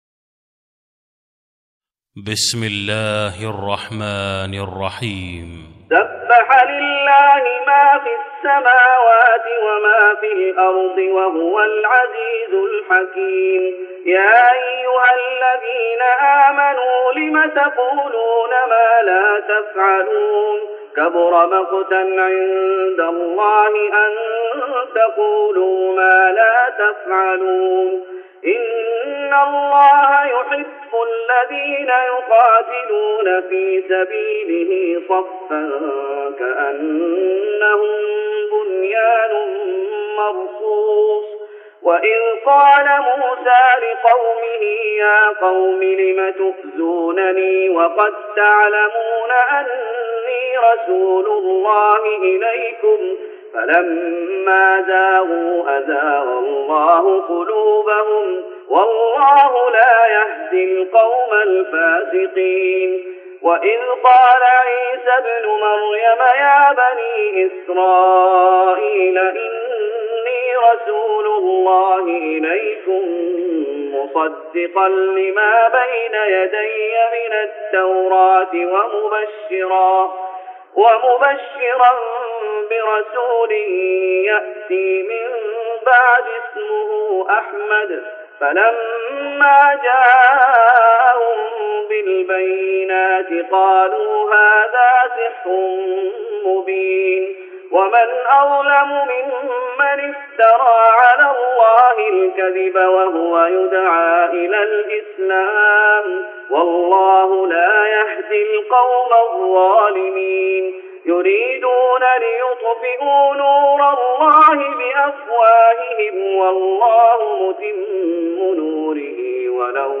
تراويح رمضان 1414هـ من سورتي الصف والجمعة Taraweeh Ramadan 1414H from Surah As-Saff and Al-Jumu'a > تراويح الشيخ محمد أيوب بالنبوي 1414 🕌 > التراويح - تلاوات الحرمين